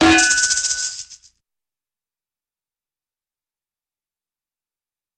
Звуки удара по голове
На этой странице собраны различные звуки ударов по голове – от мультяшных до максимально реалистичных.